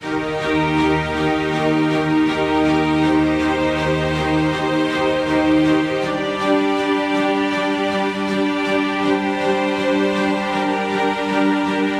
悲伤的弦乐 80bpm
Tag: 80 bpm Pop Loops Strings Loops 2.02 MB wav Key : Unknown